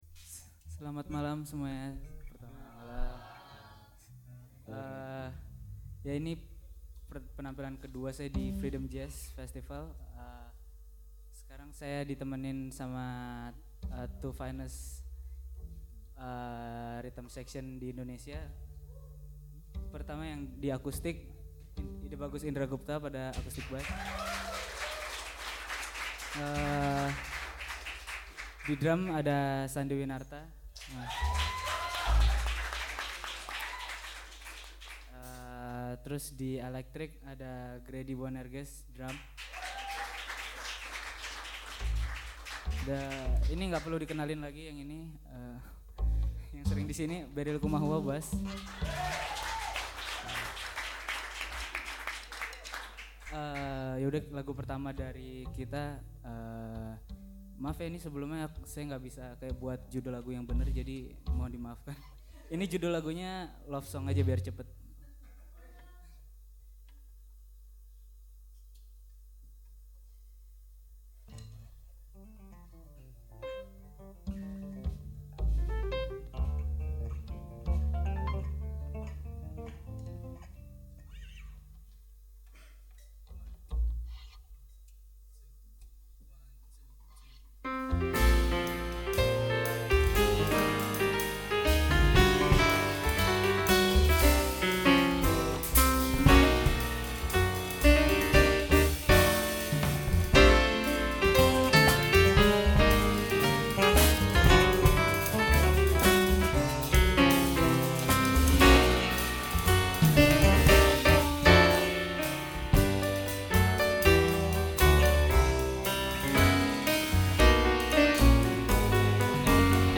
and 2 drummers